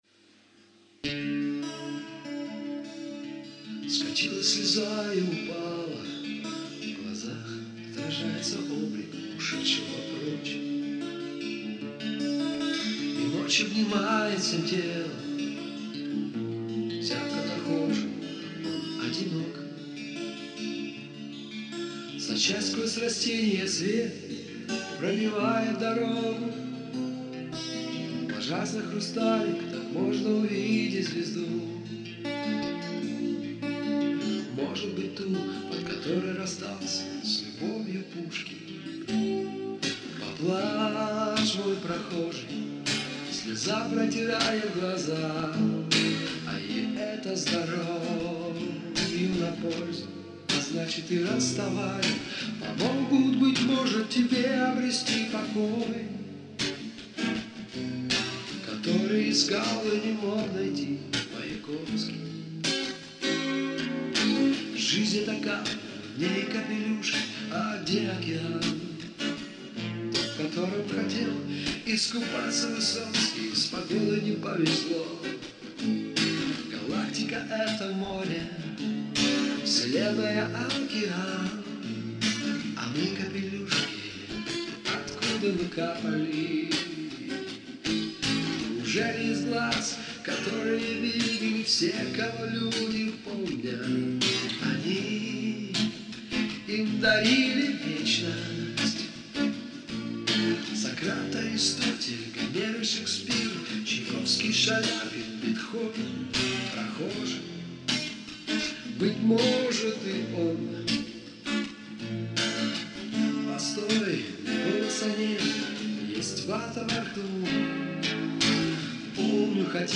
под гитару